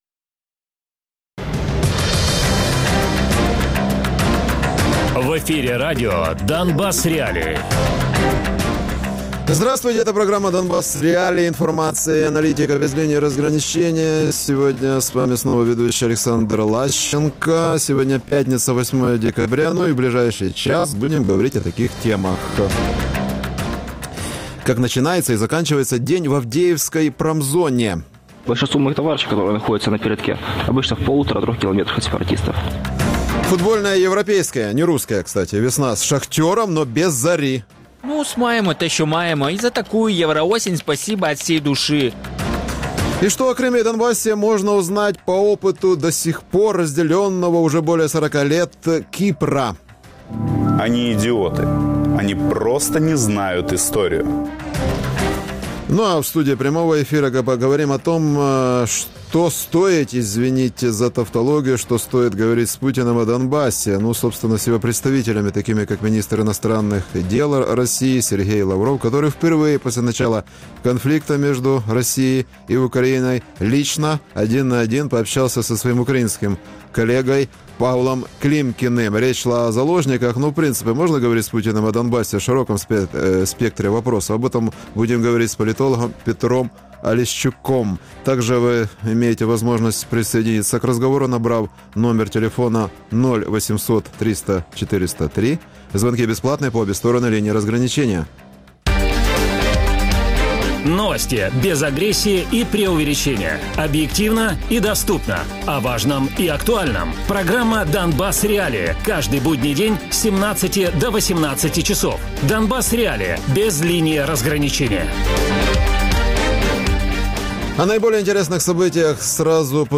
Гість: політолог